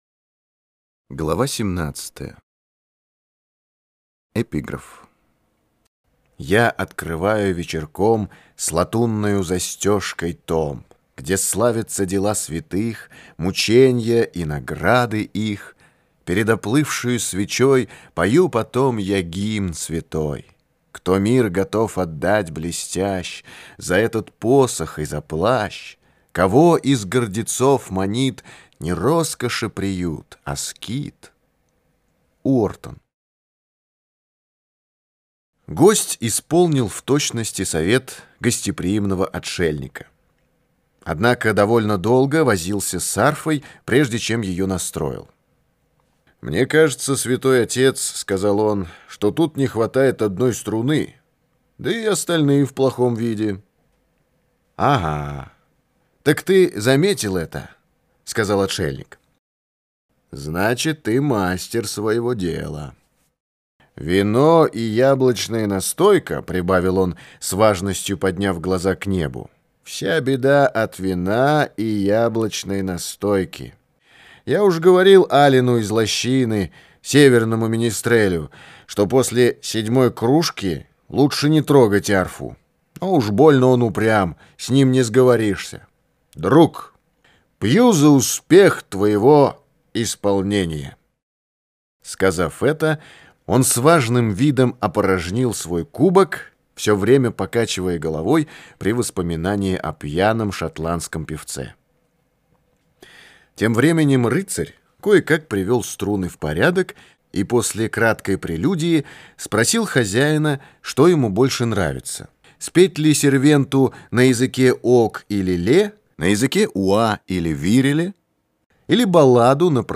Аудиокнига Айвенго - купить, скачать и слушать онлайн | КнигоПоиск
Аудиокнига «Айвенго» в интернет-магазине КнигоПоиск ✅ Зарубежная литература в аудиоформате ✅ Скачать Айвенго в mp3 или слушать онлайн